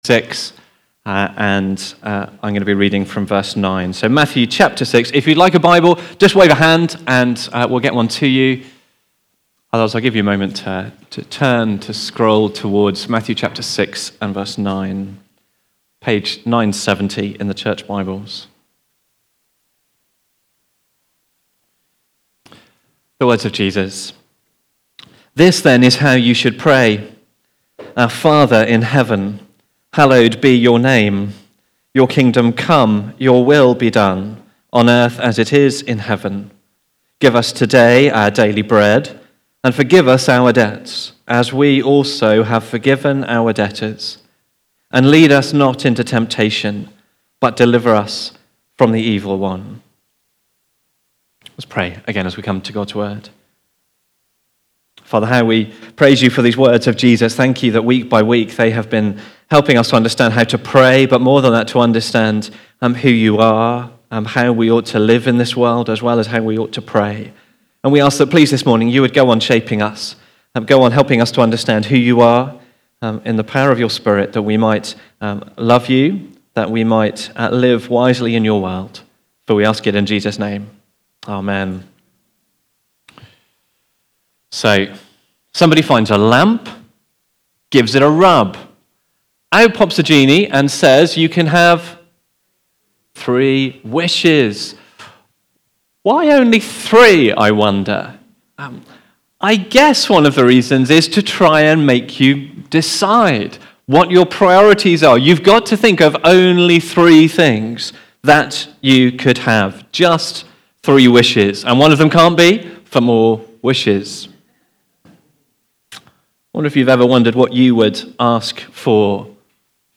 Preaching
Give Us Today Our Daily Bread (Matthew 6:9-13) from the series The Lord's Prayer. Recorded at Woodstock Road Baptist Church on 06 July 2025.